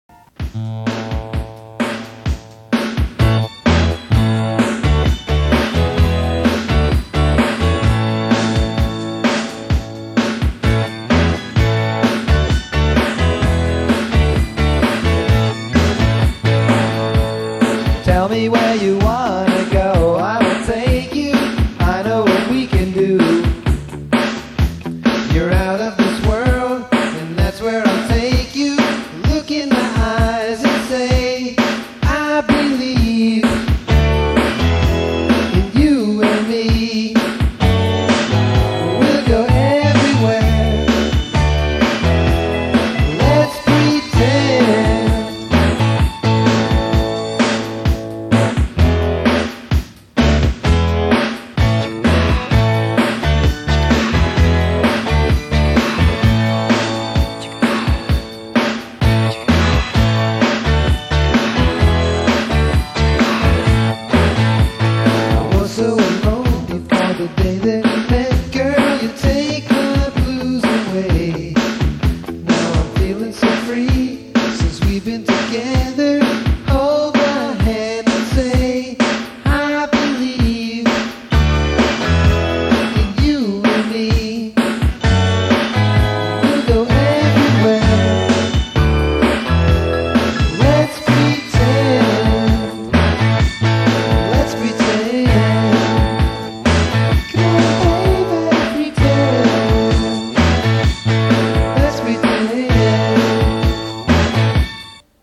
All of these were digitized from cassette.  It's silly stuff and you're aloud to laugh and even hate it.
Let's Pretend: recorded in 1985 on tascam model 38 1/2-inch 8track with drum loop stolen from the intro of some 45.
The "synth" is a white toy Casio with miniature keys and presets only.